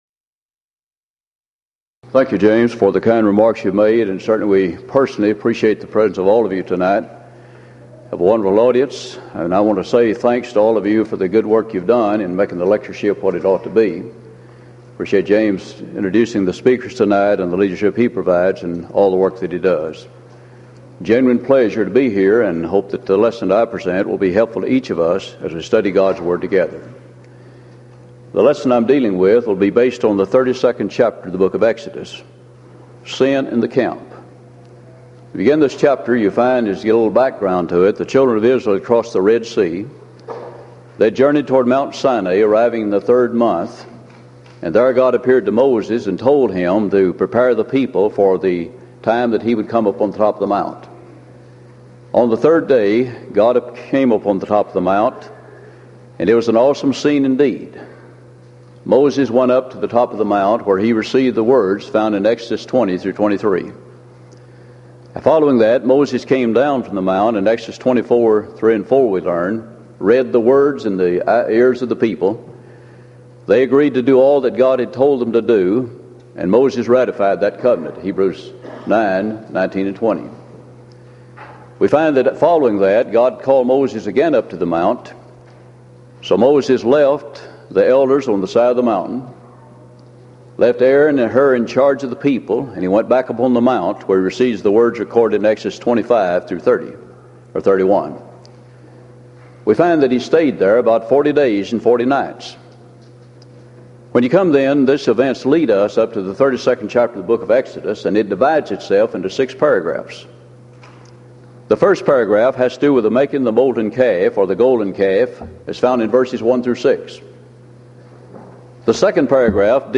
East Tennessee School of Preaching Lectureship Event: 1997 East Tennessee School of Preaching Lectures Theme/Title: Studies In The Book of Exodus